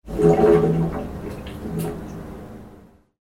I recorded 2 hours of washing machine noises just to get this noise:
Connected Microbrute and Dark Energy synths.